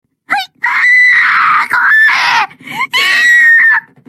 Play Nakiri Ayame Scream - SoundBoardGuy
nakiri-ayame-scream.mp3